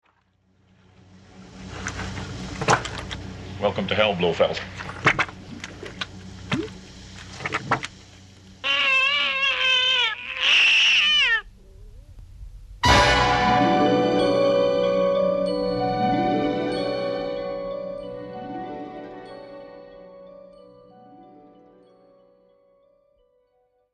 Original Mono Remixed DTS/5.1